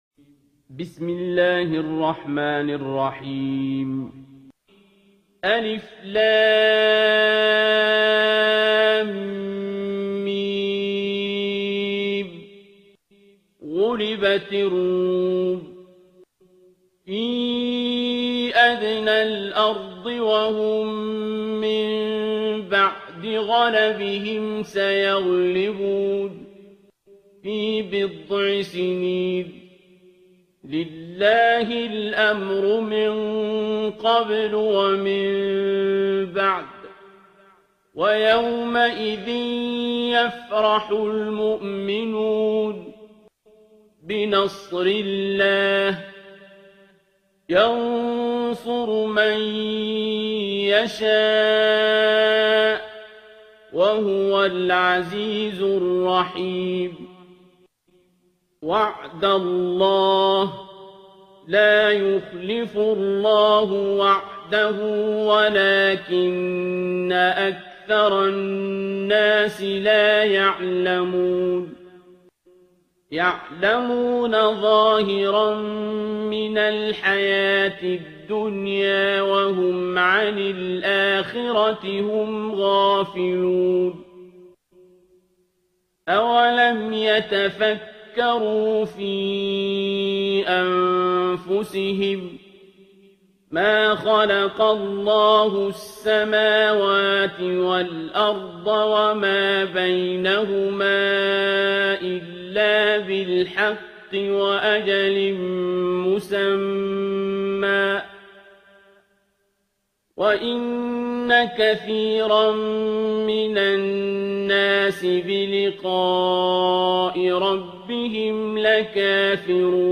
ترتیل سوره روم با صدای عبدالباسط عبدالصمد